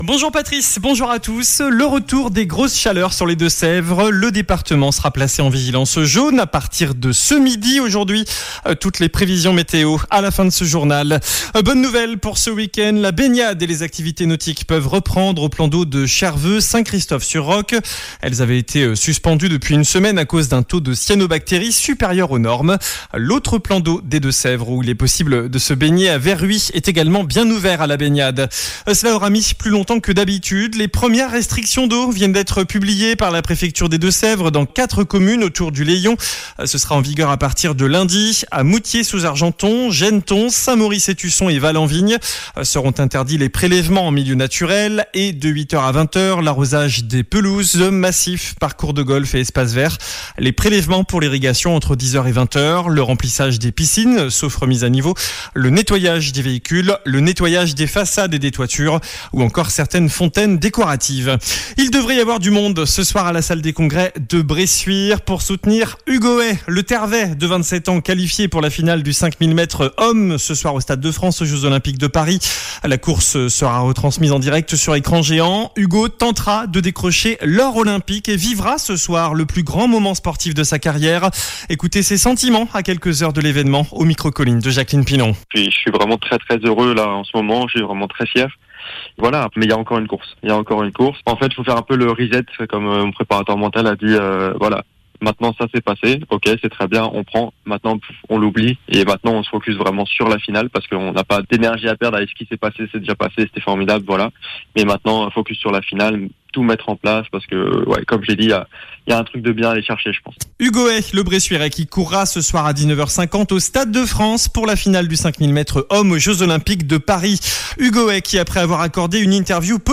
JOURNAL DU SAMEDI 10 AOÛT ( MIDI )